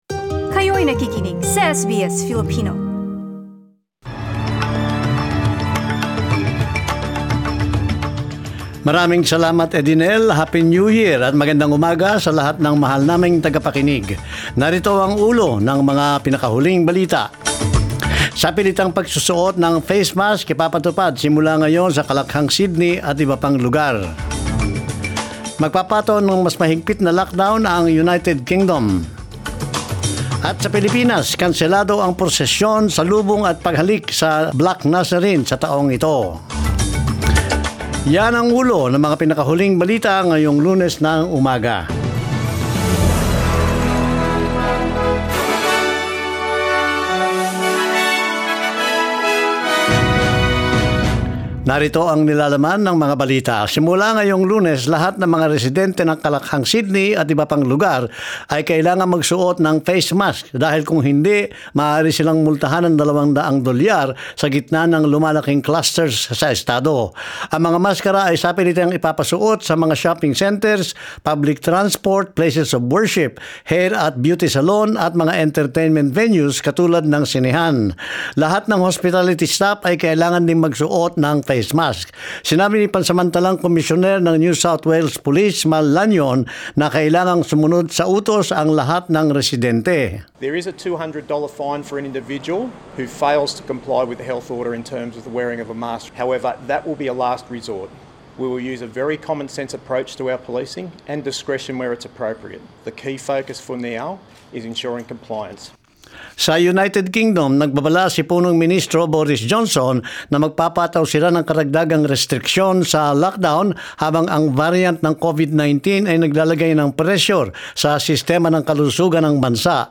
Mga balita ngayong ika 4 ng Enero